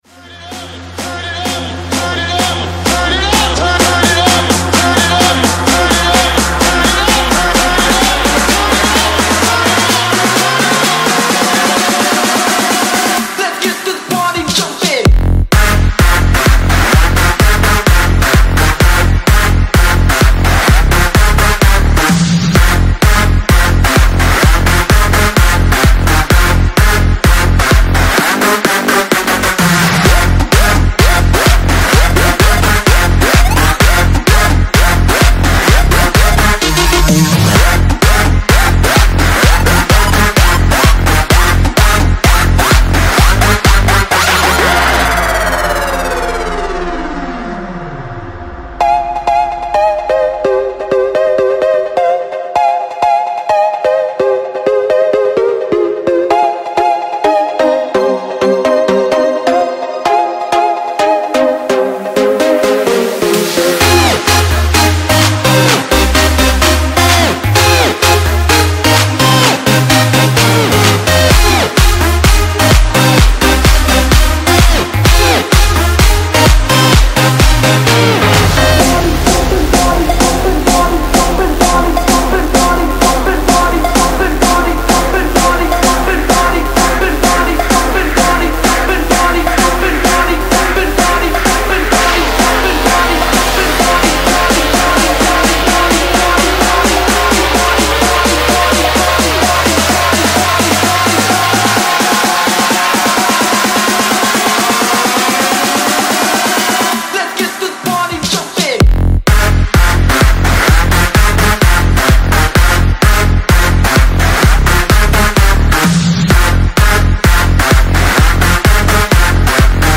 BPM64-128
Audio QualityPerfect (Low Quality)